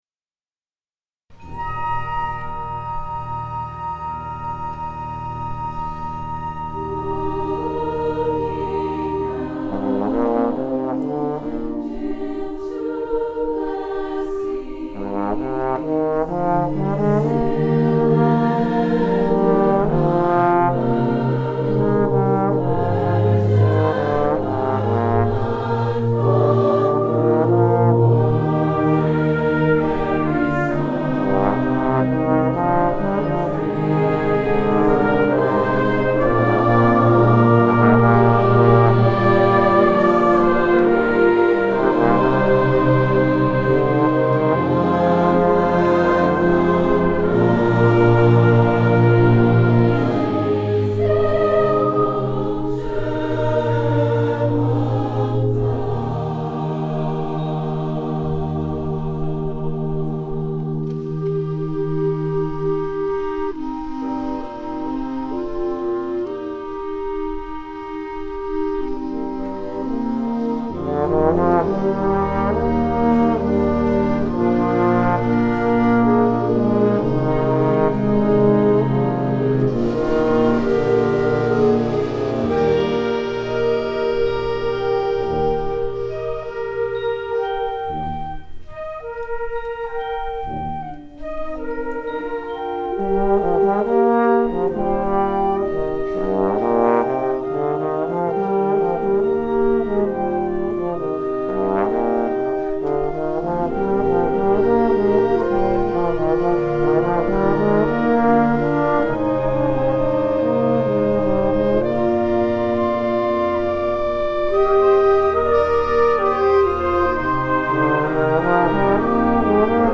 Concerto for Bass Trombone, Symphonic Band & Choir